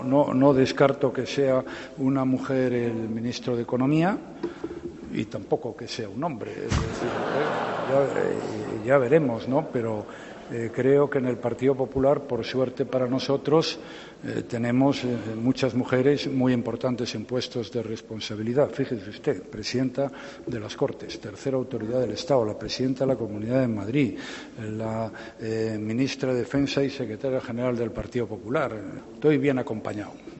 "No descarto que sea una mujer el (nuevo) ministro de Economía y tampoco que sea un hombre. Ya veremos", ha respondido Rajoy a una pregunta sobre el sucesor de De Guindos durante su intervención en el almuerzo-coloquio del Foro ABC, en el que ha destacado que, "por suerte", en el PP hay "muchas mujeres" con puestos de responsabilidad.